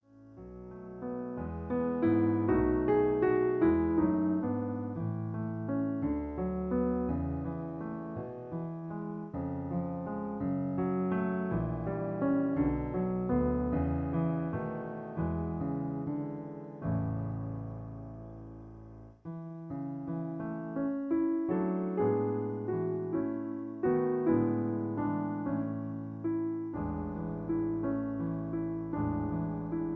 Original key (A). Piano Accompaniment